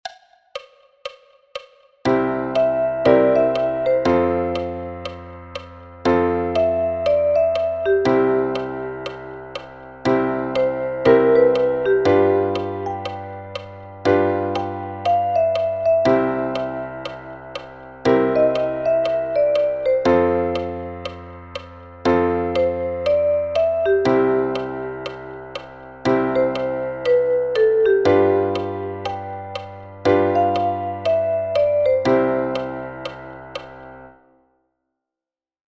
Dieser Country-Blues-Klassiker, komponiert von Mississippi John Hurt (1893 – 1966).